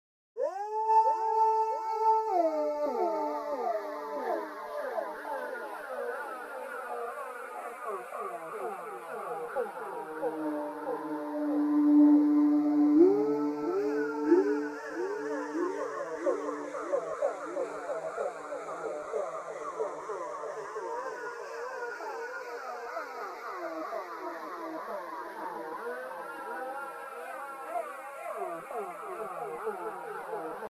Solo (mélodique) de synthé
-2) La partie multiple à la blanche pointée (tous les 3 temps) passée dans un écho à la croche pointée (3/4 de temps) avec 4 répétitions.